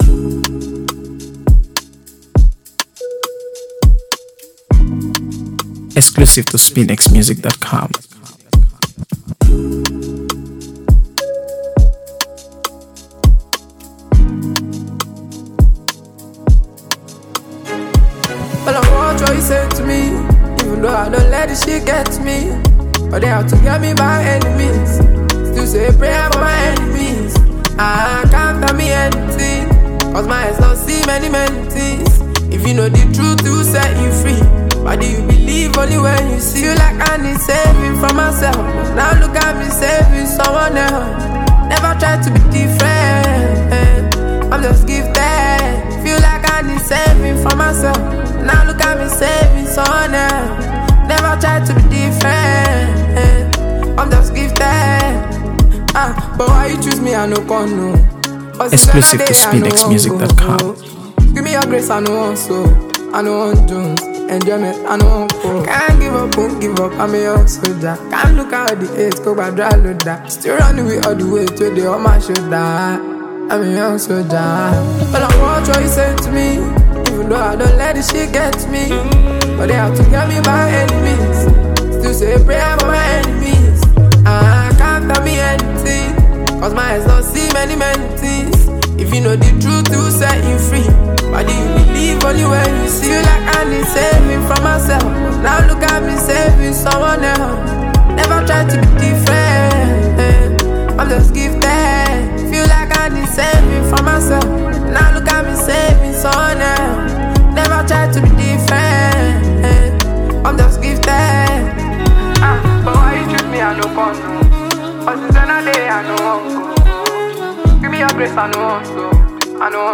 AfroBeats | AfroBeats songs
smooth production, catchy rhythm